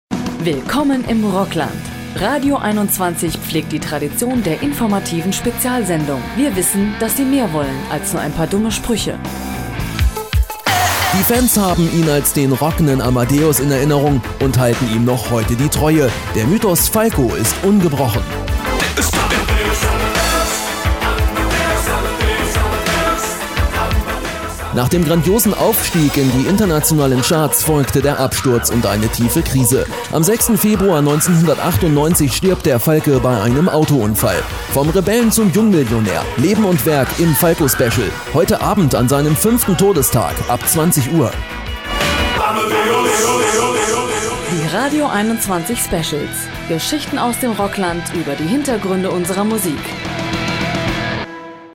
deutscher Sprecher.
norddeutsch
Sprechprobe: Industrie (Muttersprache):